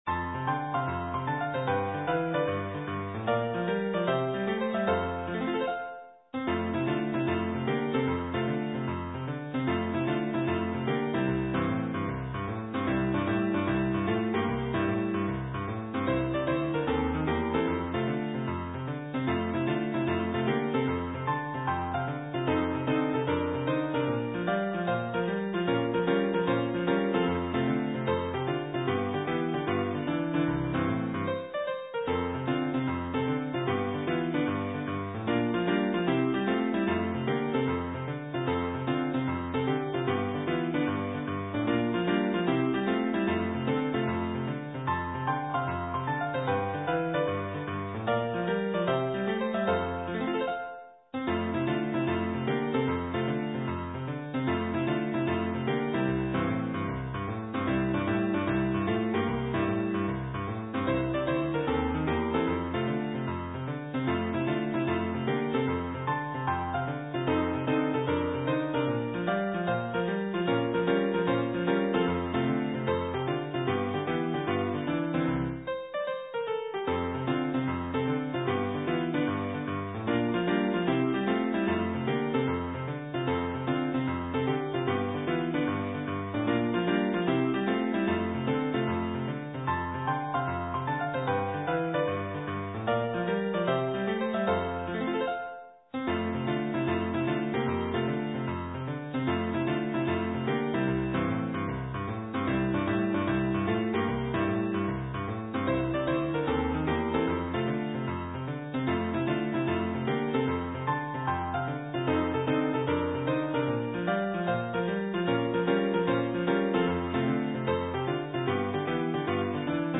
schneeklavier.mp3